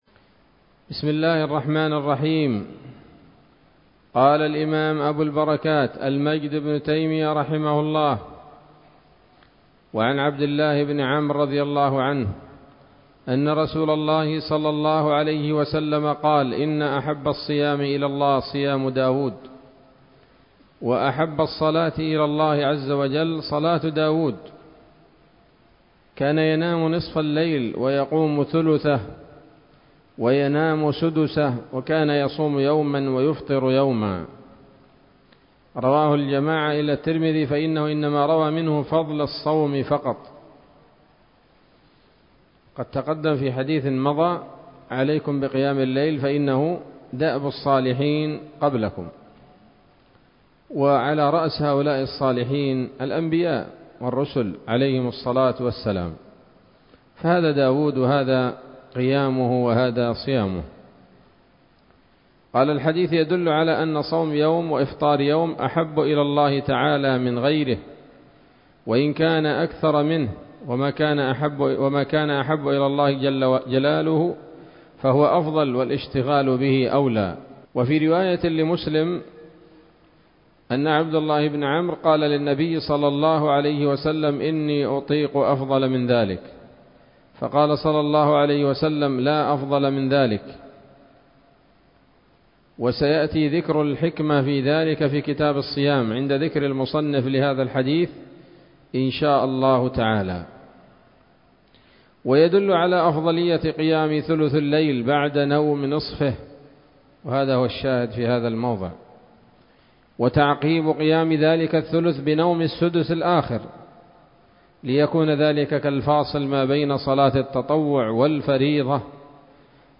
الدرس الثامن والعشرون من ‌‌‌‌أَبْوَابُ صَلَاةِ التَّطَوُّعِ من نيل الأوطار